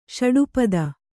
♪ ṣaḍu pada